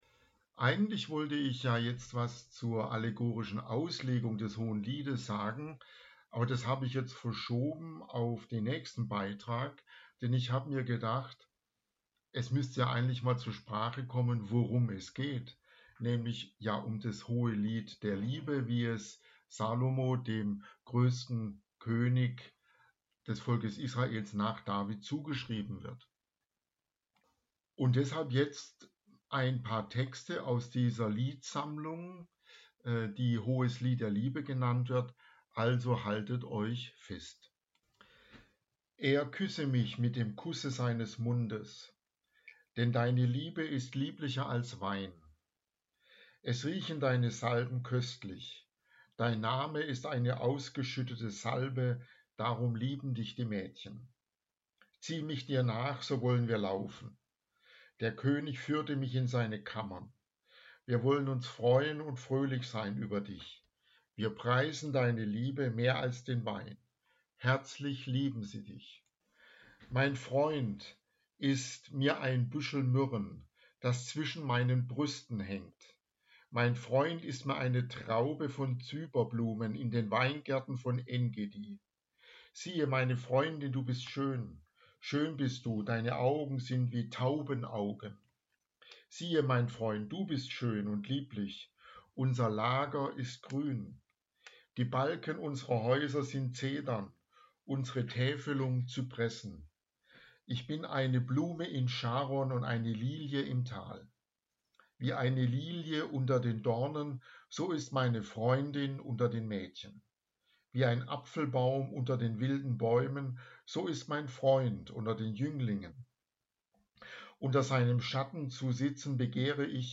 Theologie der Zärtlichkeit - Textlesung Hohes Lied